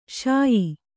♪ śayi